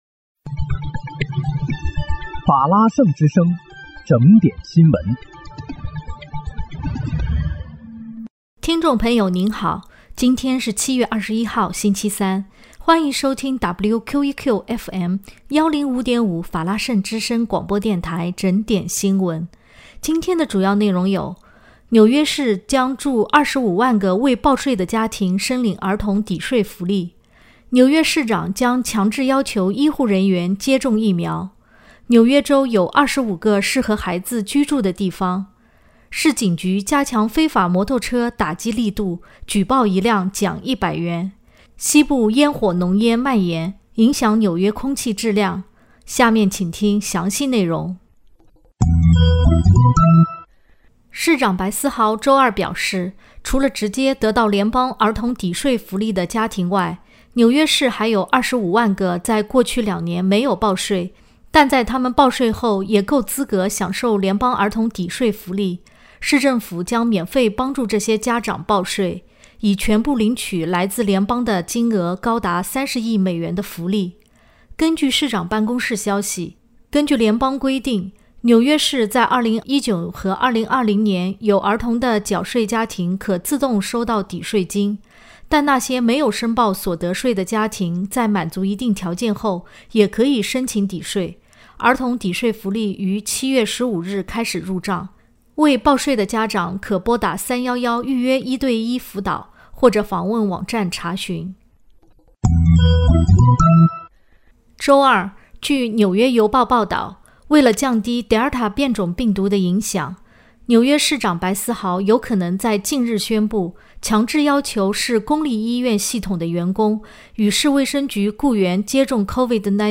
7月21日（星期三）纽约整点新闻